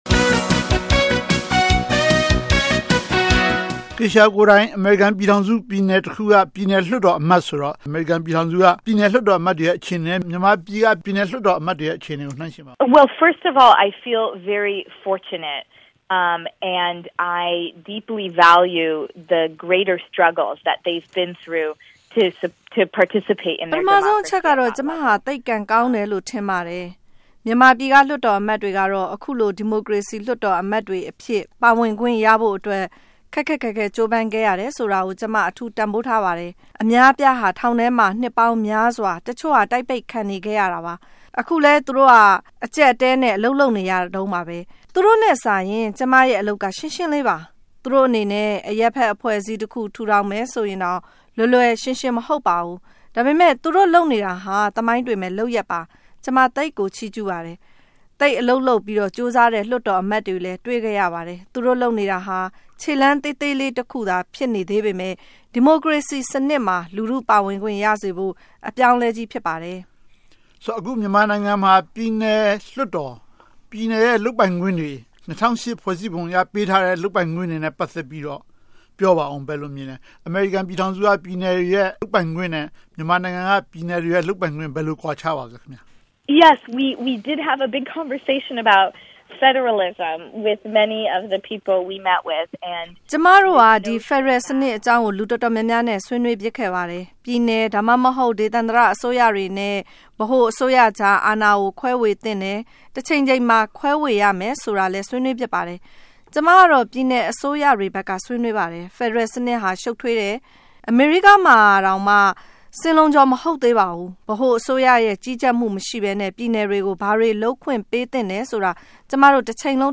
ဆက်သွယ်မေးမြန်း ဆွေးနွေးထားပါတယ်။